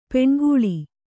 ♪ peṇgūḷi